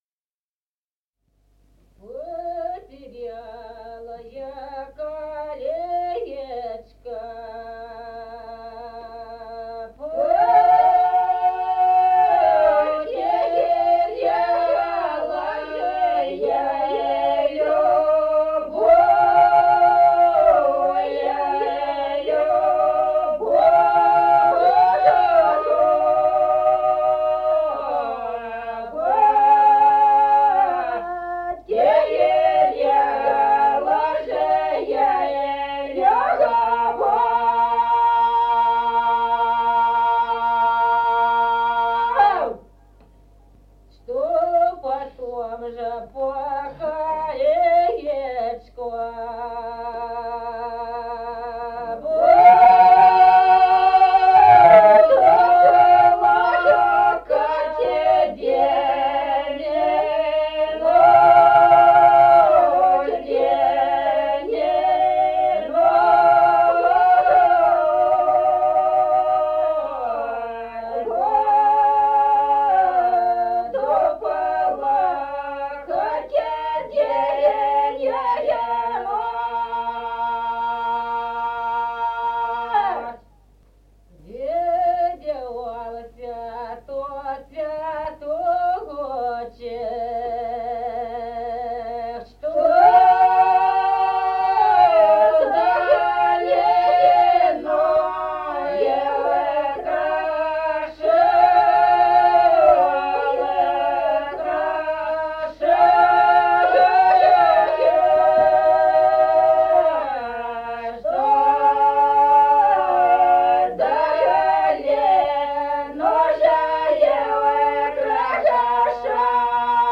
Народные песни Стародубского района «Потеряла я колечко», лирическая, городская.
1953 г., с. Остроглядово.